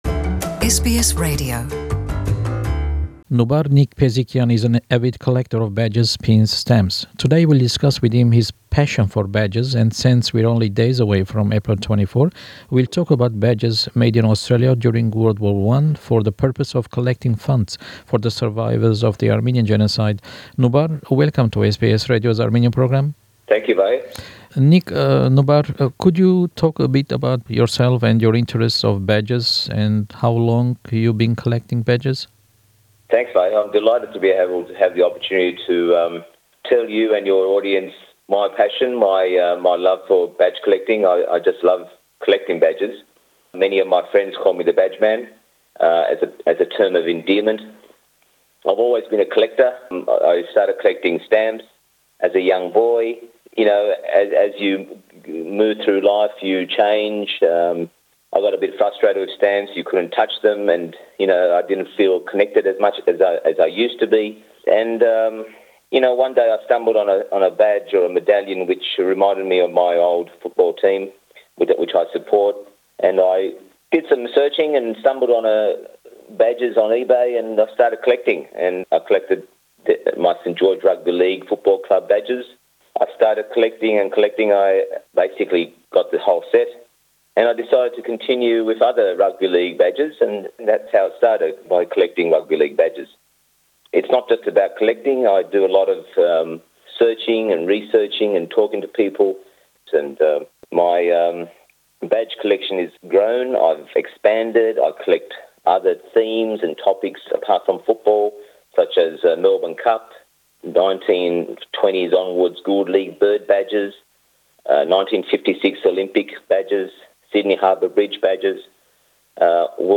հարցազրոյցի